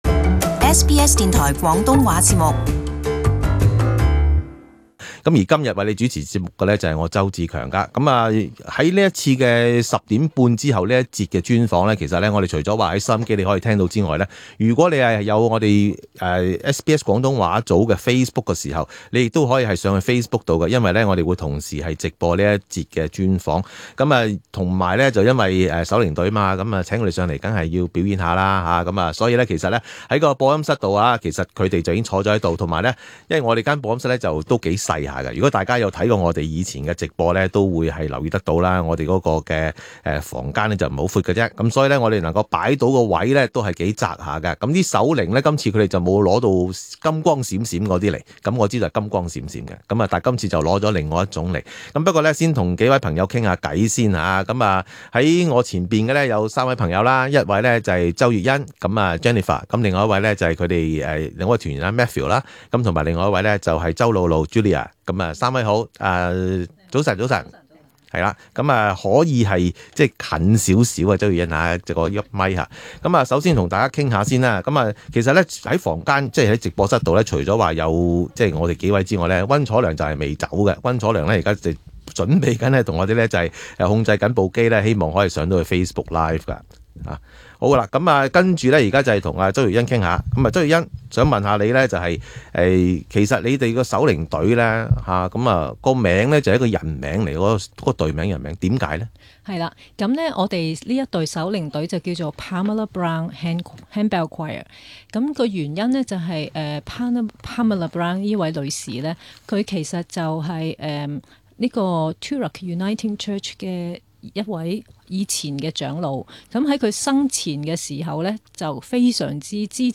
【专访】访问 Pamela Brown Handbell Choir 手铃队